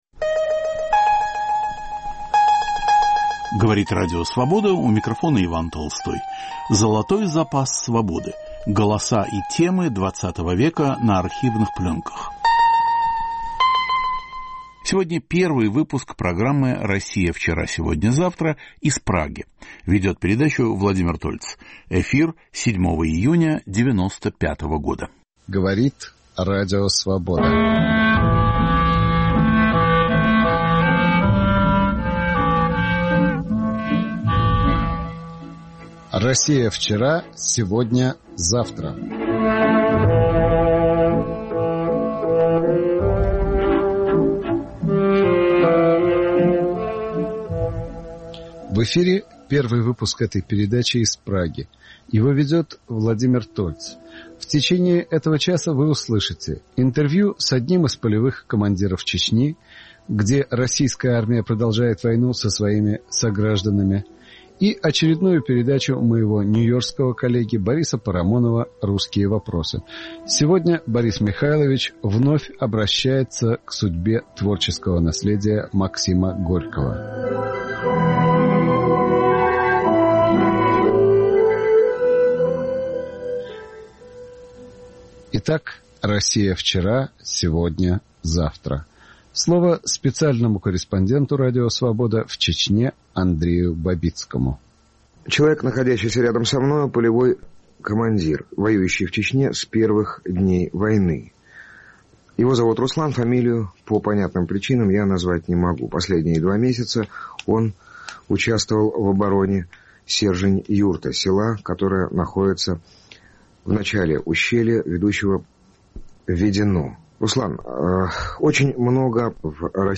Интервью с чеченским полевым командиром, во второй части - "Русские вопросы" Бориса Парамонова - о судьбе творческого наследия Максима Горького. Редактор и ведущий Владимир Тольц.